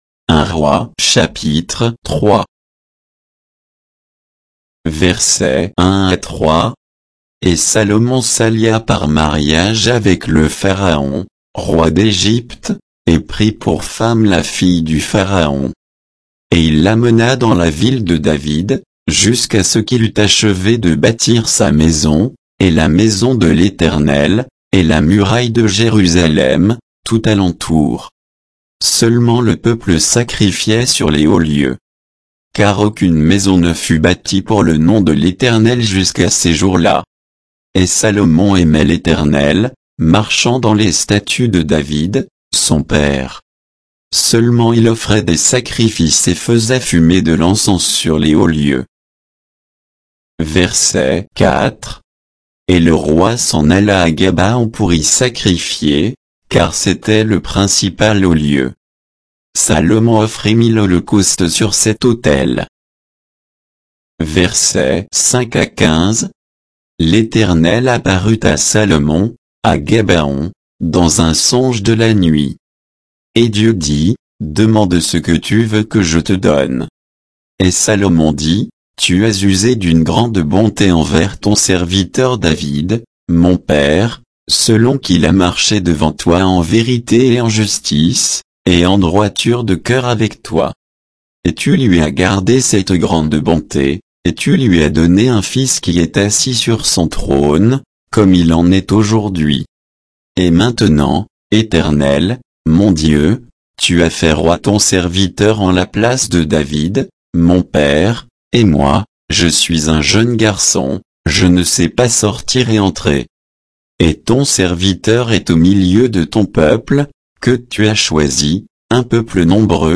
Bible_1_Rois_3_(sans_notes,_avec_indications_de_versets).mp3